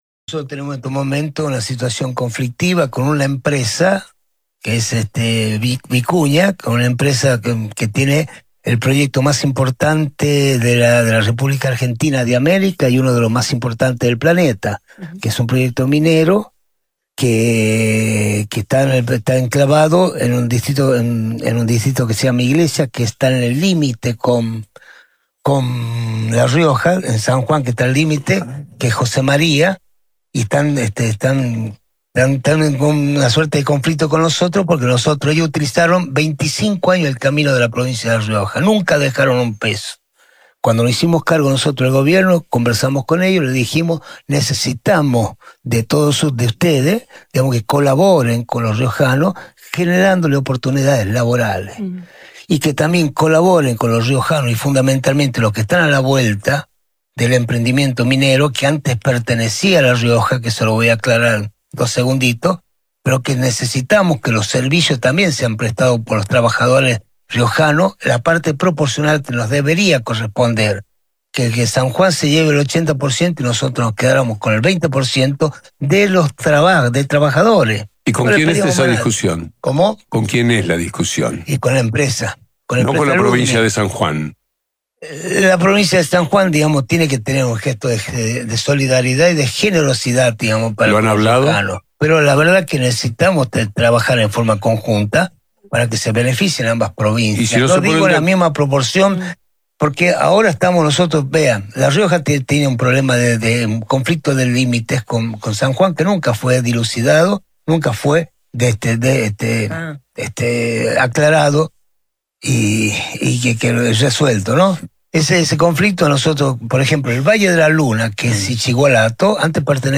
Cabe destacar que el tema volvió a entrar en terreno de discusión cuando el propio gobernador de La Rioja, Ricardo Quintela, insistió en una entrevista con una radio de Buenos Aires, sobre el reclamo riojano por participación en el proyecto Josemária y la soberanía sobre otros territorios que hoy están en jurisdicción sanjuanina.